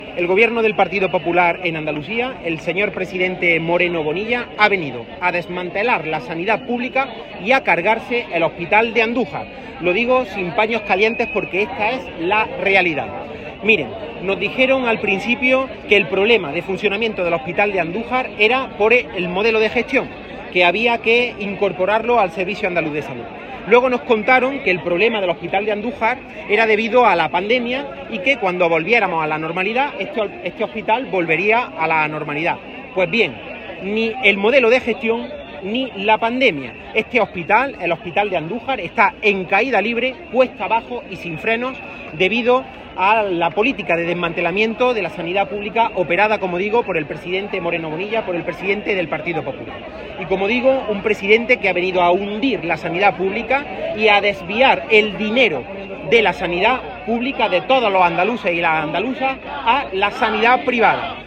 Concentración de protesta contra la Junta por el desmantelamiento del centro hospitalario
El responsable socialista ha hecho estas declaraciones en la concentración de protesta celebrada junto al centro hospitalario en la que ha participado en su doble condición de alcalde de Arjona y secretario general del PSOE de Jaén.
Cortes de sonido